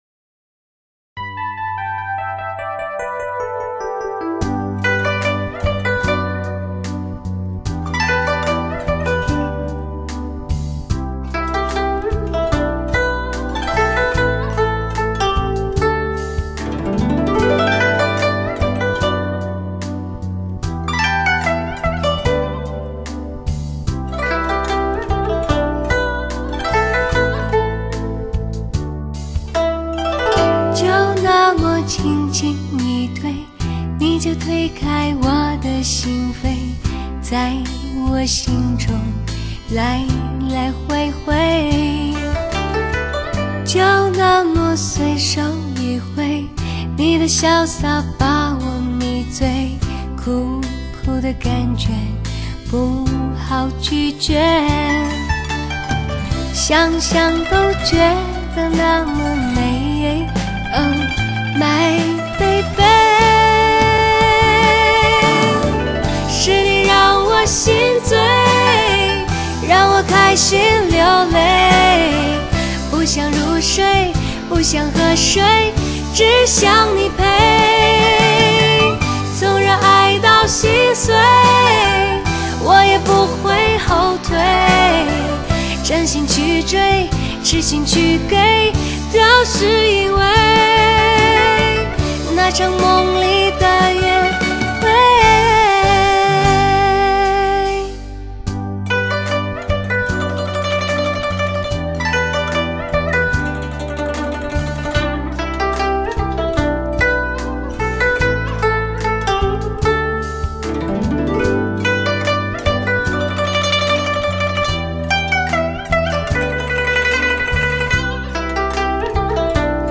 完全地听完次碟，除了感叹录音质量的极品，更是喜欢选曲的精心、演唱的全心投入，每一首歌曲都全部代表了一份真心。